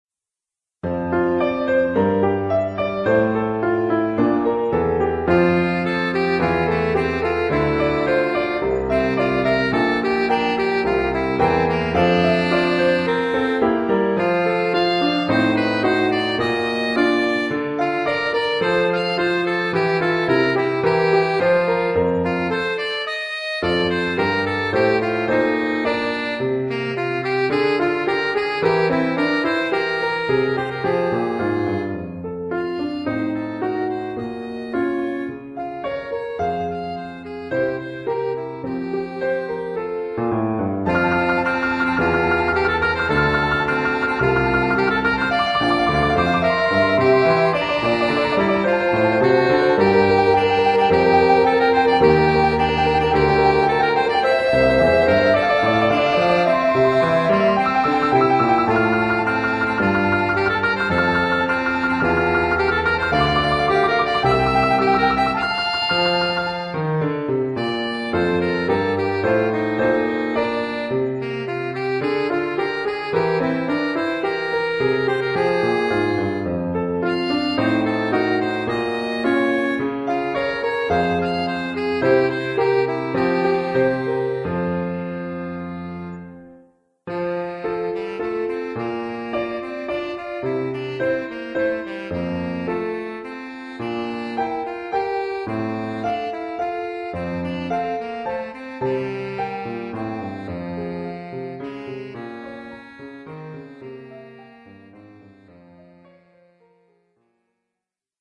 Oeuvre pour saxophone alto et piano.